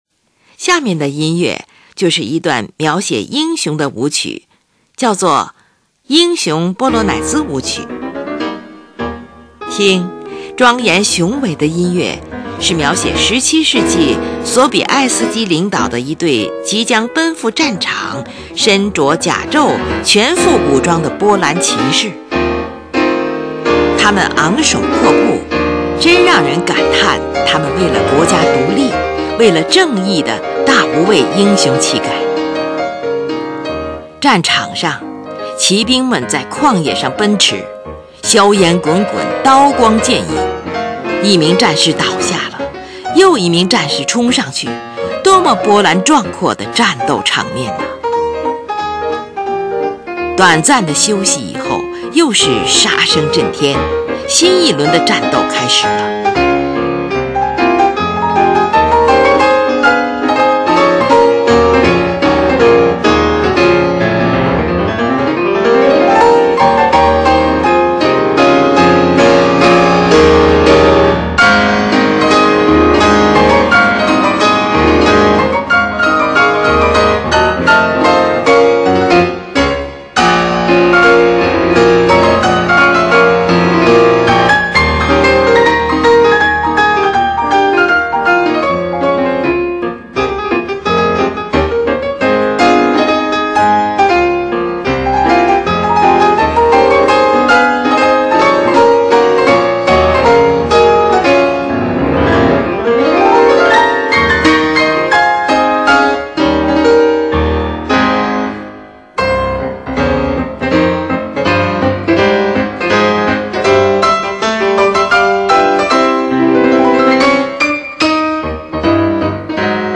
它的特点是：中速，3/4拍，乐句末音常落在第二拍或第三拍，气势辉煌。
接着，出现了一段平静的旋律，好像激烈的战斗告一段落，战士们在进行短暂的休息。
第三部分，尾声仍是降A大调，是第一部分的回应，情绪、性格与主题一致。